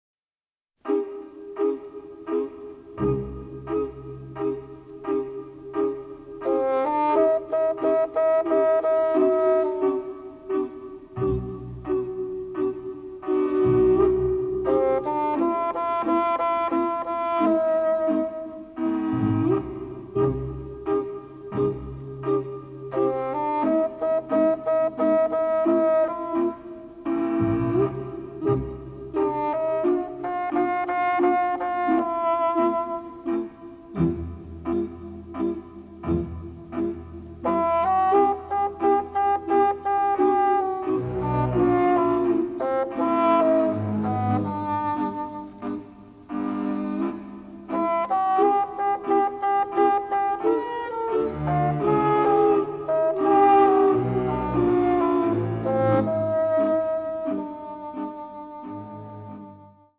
Film Versions (mono)